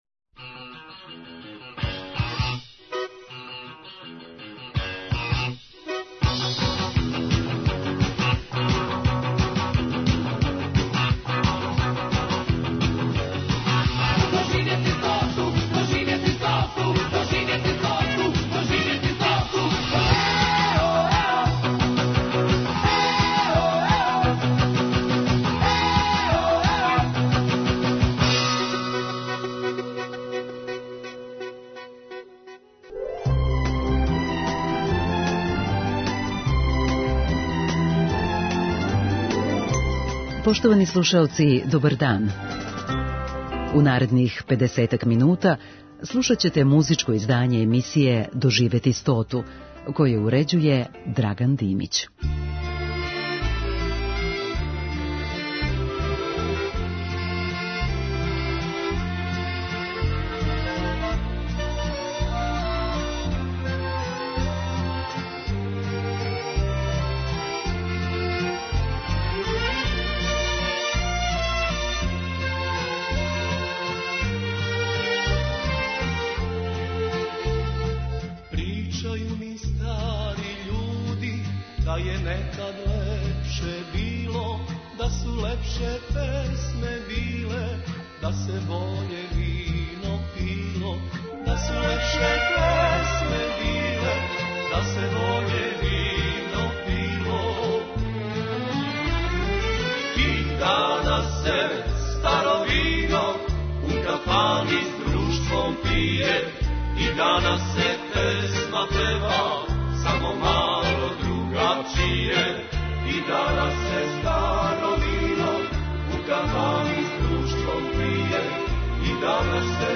У оквиру празничне програмске шеме, слушаћете музичко издање емисије „Доживети стоту“ са одабраним староградским песмама.